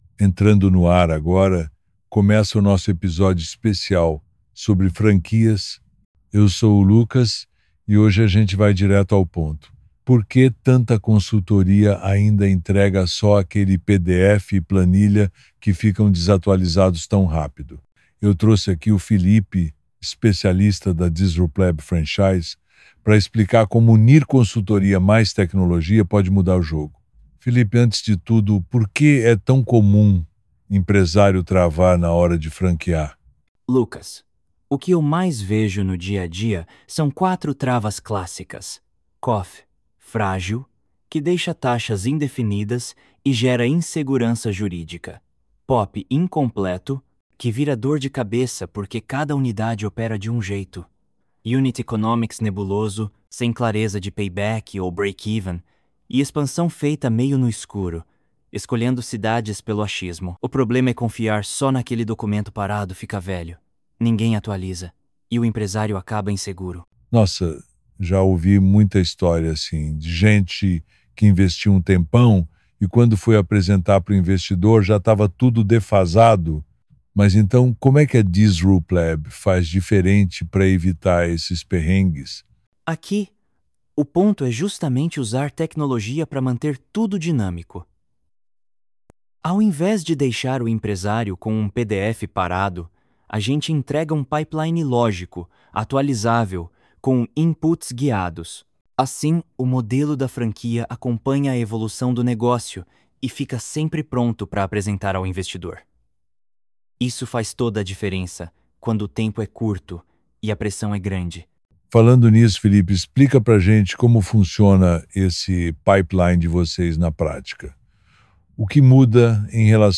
AI_Podcast_Transforme-Seu-Negocio-em-Franquia-em-90-Dias-com-a-Disruplab.mp3